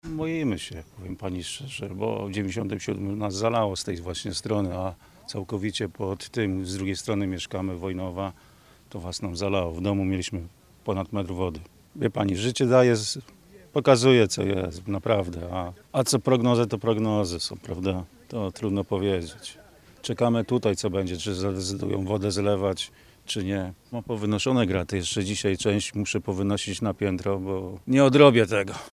Pomimo wysiłków mieszkańców i zapewnień o wzmocnionej infrastrukturze, lęk przed powtórką wydarzeń z 1997 roku wciąż jest obecny. Jeden z mieszkańców z niepokojem przypomina, jak ich dom został wtedy zalany.